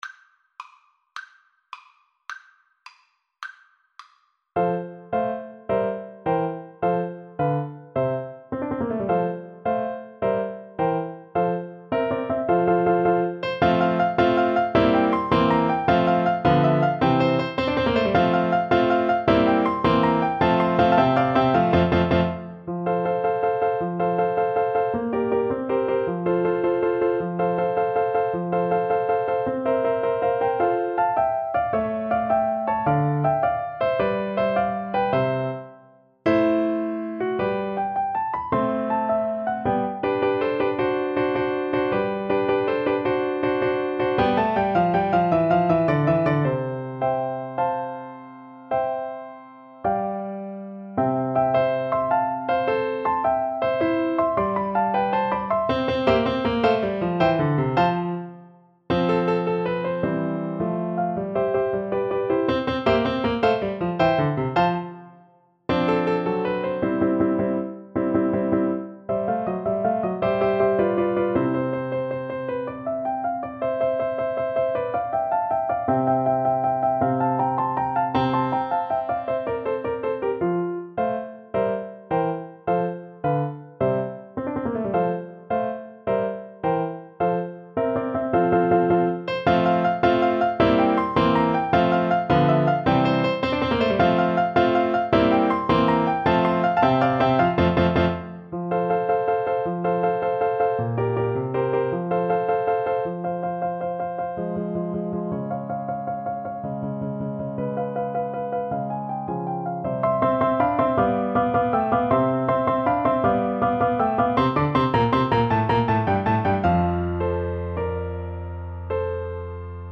6/8 (View more 6/8 Music)
.=106 Allegro vivace (View more music marked Allegro)
Classical (View more Classical Alto Recorder Music)